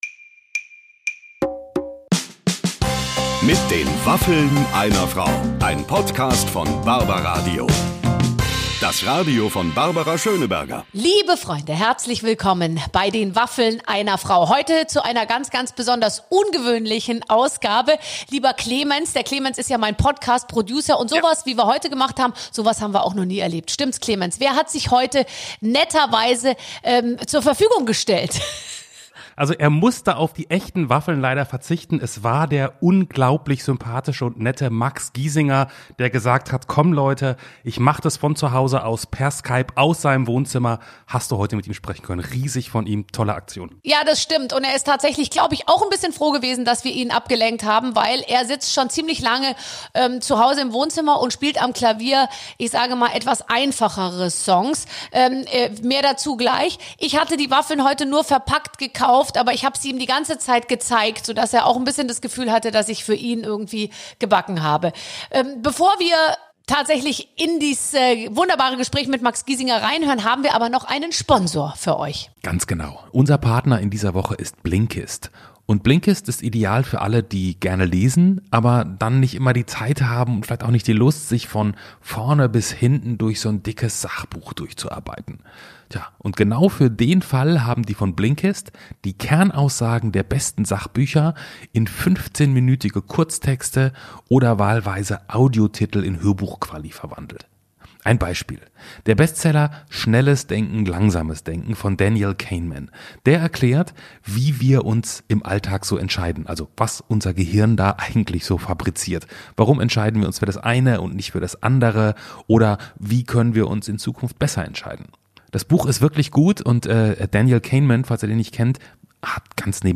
Musiker Max Giesinger kann dieses Mal nicht in Barbara Schönebergers Waffelstudio kommen und ist unser erster Waffelgast, der per Skype dazugeholt wird. Max Giesinger verrät im Podcast das Rezept der Giesinger-Bolognese.